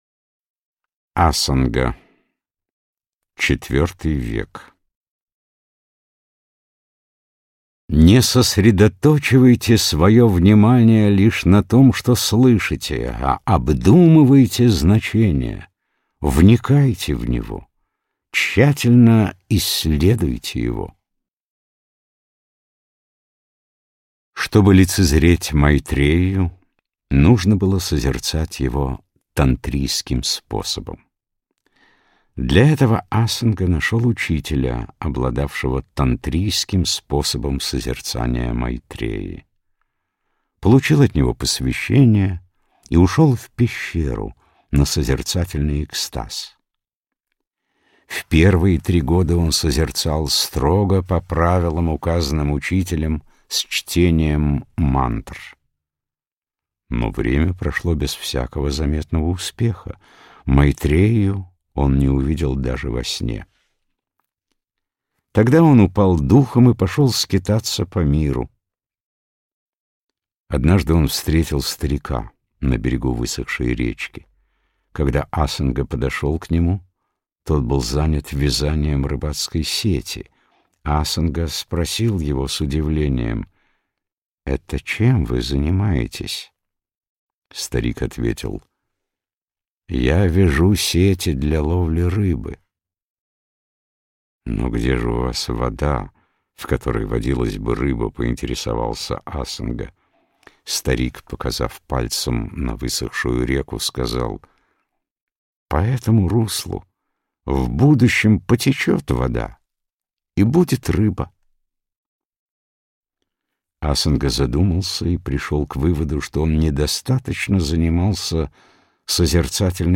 Аудиокнига Мудрость тысячелетий. Буддийские изречения, притчи, афоризмы | Библиотека аудиокниг